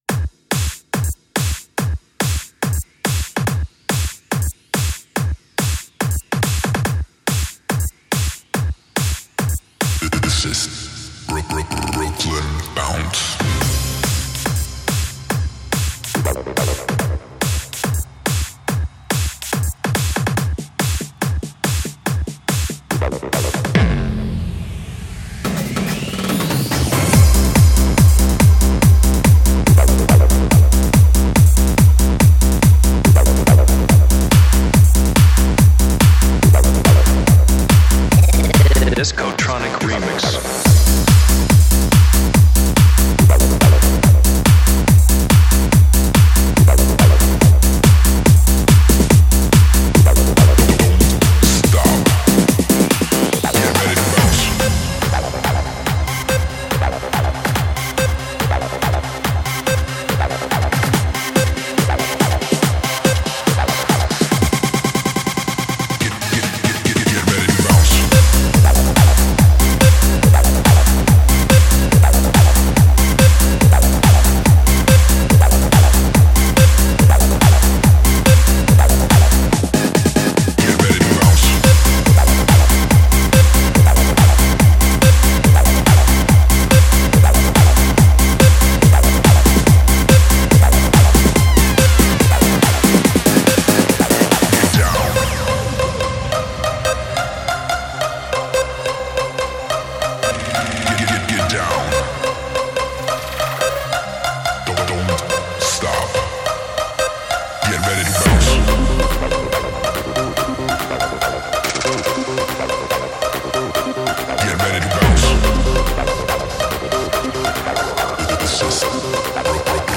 Жанр: Dance/Electronic